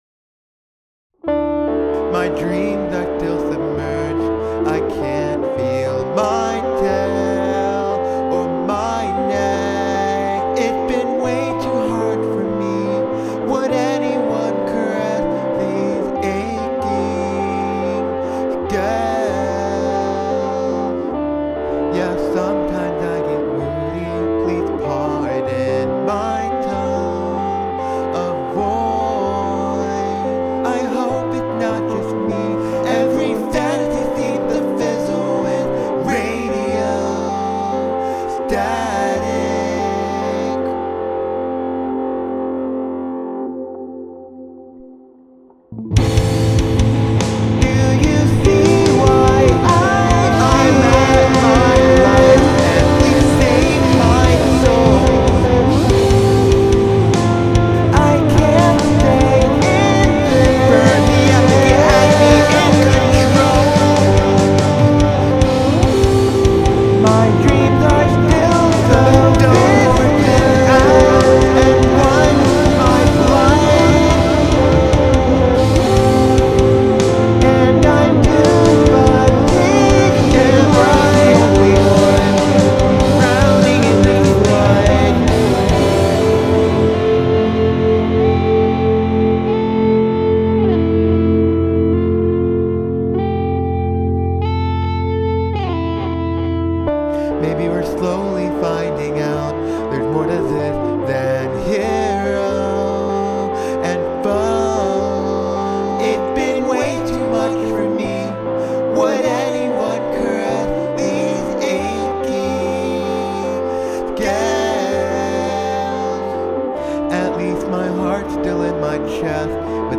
Music / Rock
indie rock dragon song